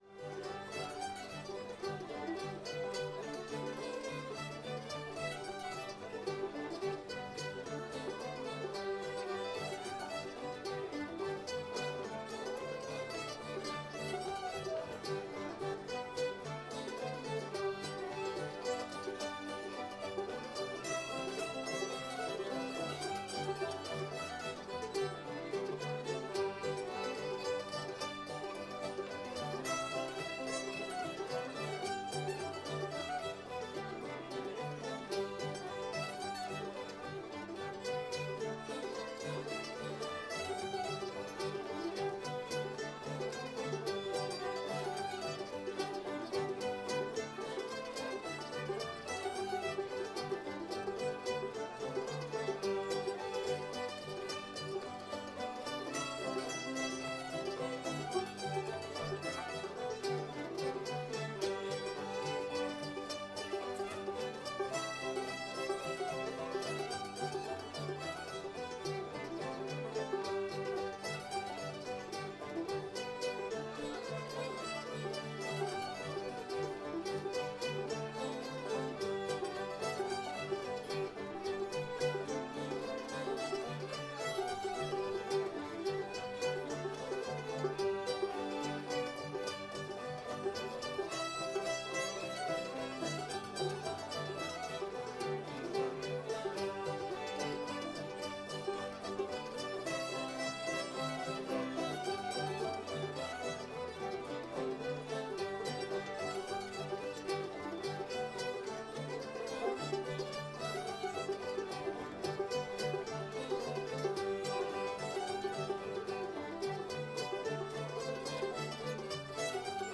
cuffy [G]